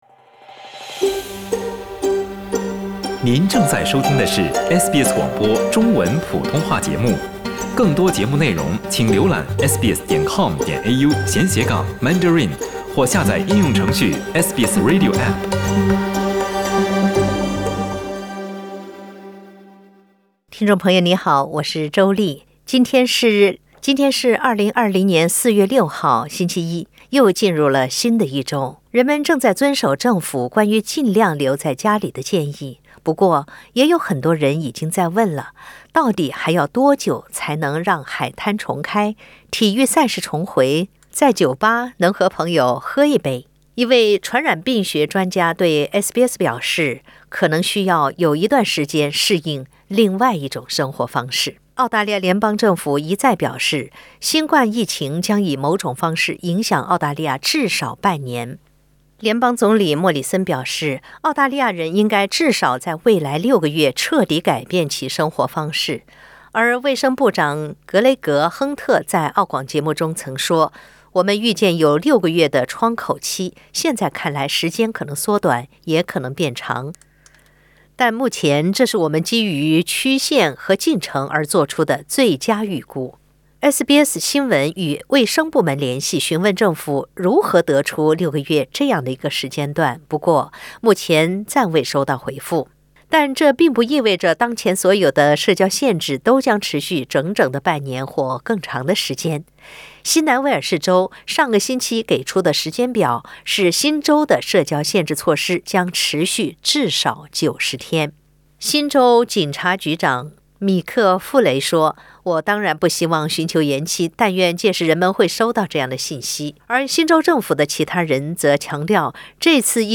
点击上方图片收听录音报道。